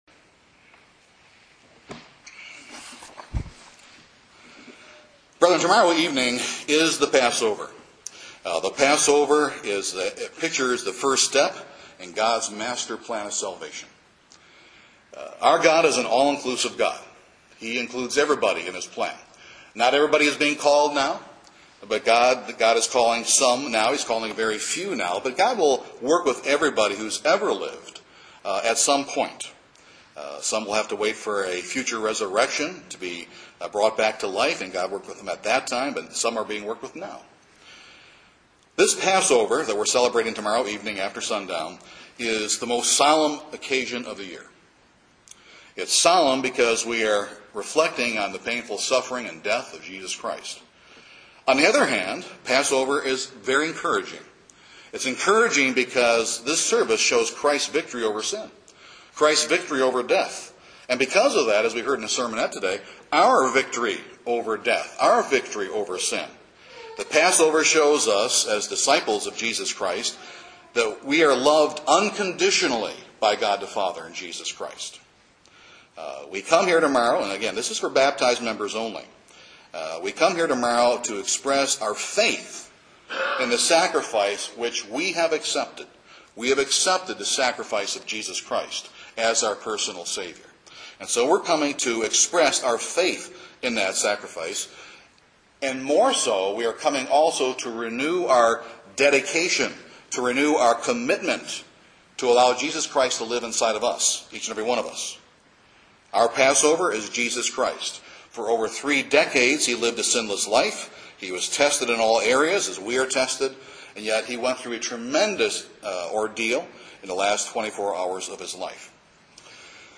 This sermon reveals those eternal life giving details.